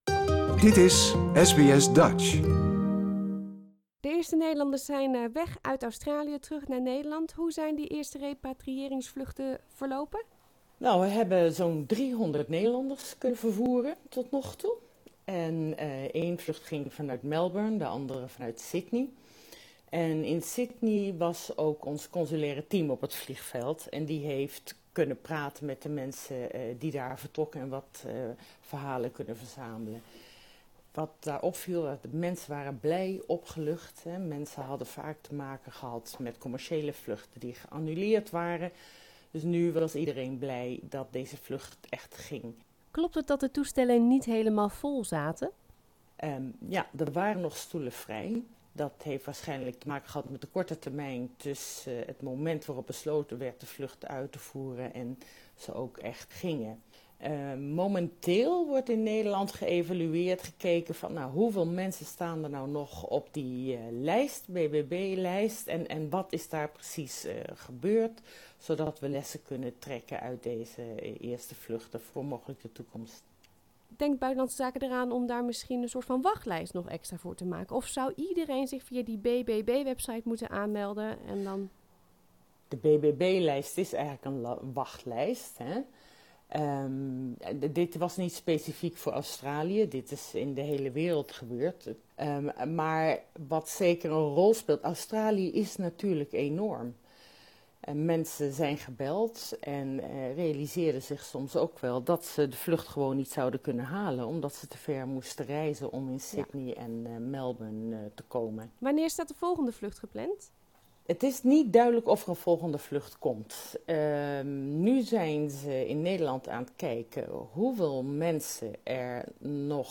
In totaal 300 gestrande Nederlanders zijn met speciale repatriëringsvluchten vanaf Melbourne en Sydney naar huis gevlogen. Een grote operatie die niet op alle punten vlekkeloos verliep. De Nederlandse ambassadeur Marion Derckx geeft een update.